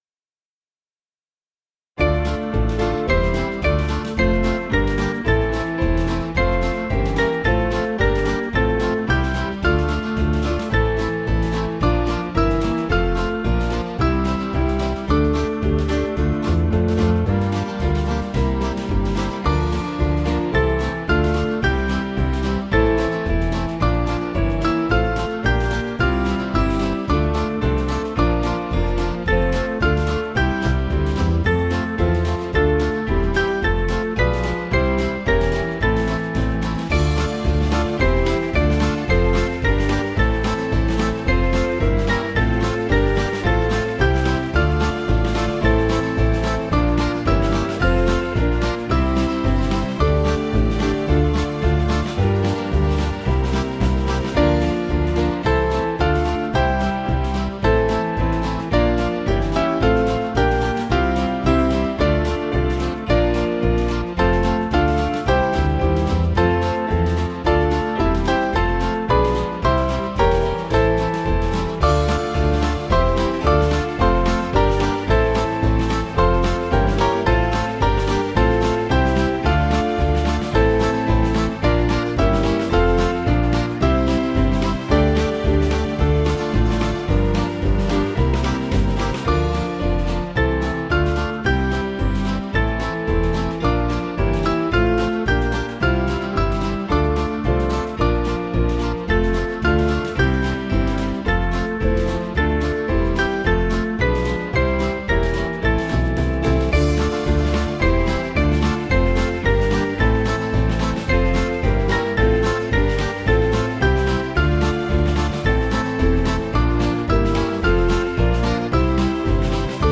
Small Band
(CM)   5/Dm 485.2kb